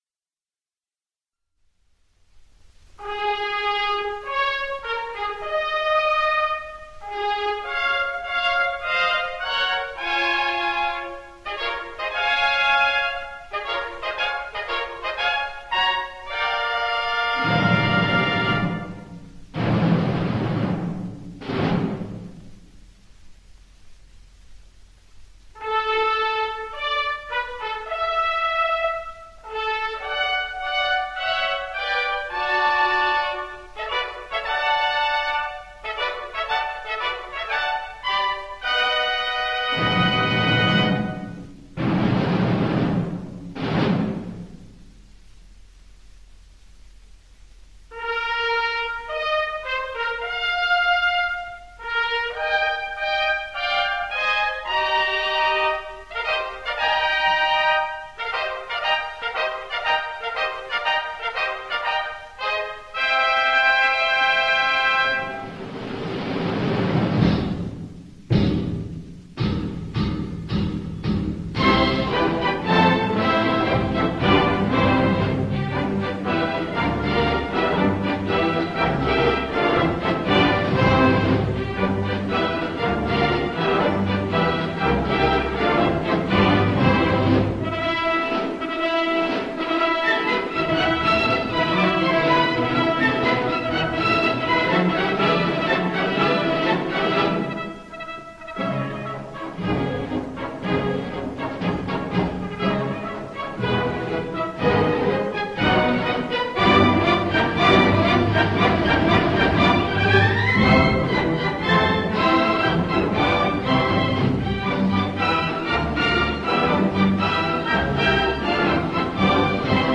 Album: nahráno v EMI Abbey Road (1938)